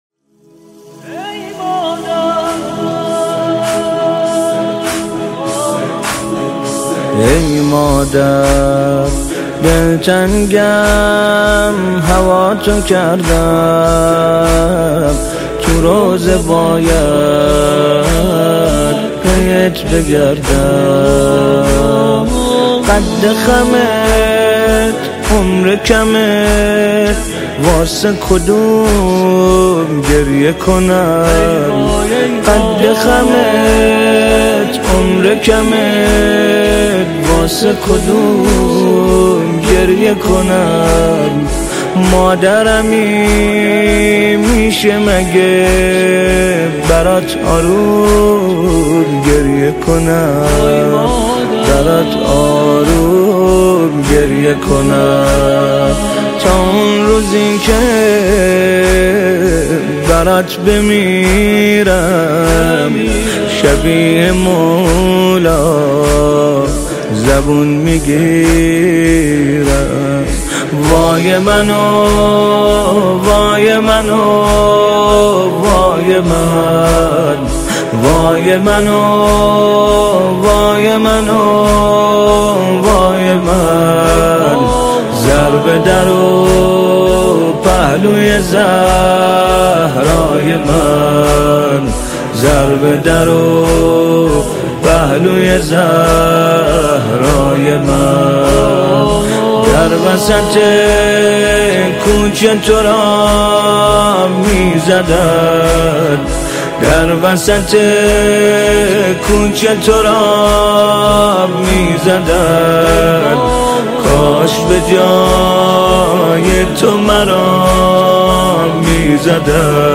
نماهنگ جدید فاطمیه